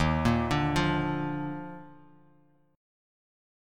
EbM7sus2 Chord
Listen to EbM7sus2 strummed